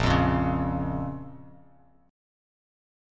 Bbm Chord
Listen to Bbm strummed